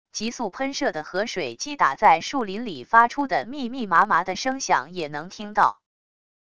急速喷射的河水击打在树林里发出的密密麻麻的声响也能听到wav音频